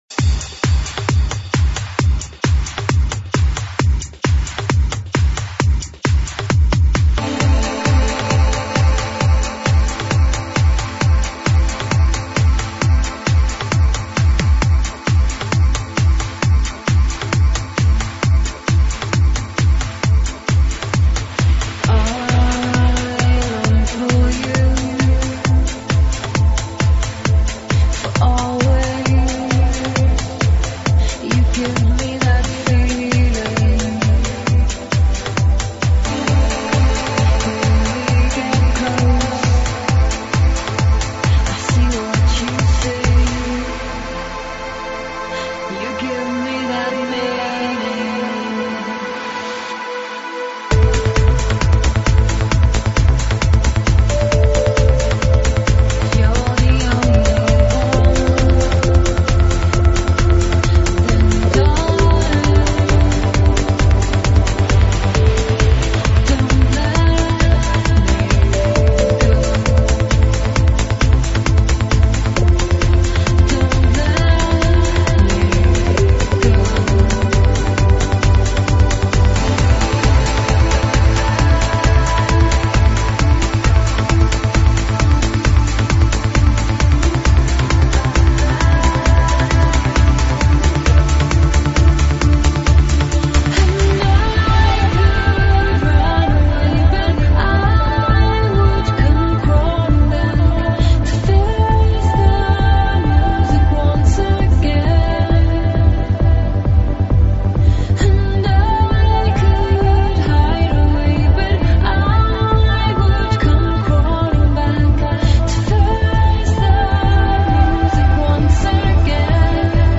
транс сборник